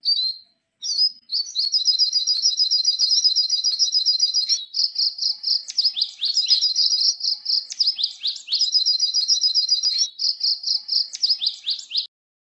黄雀叫声录制音频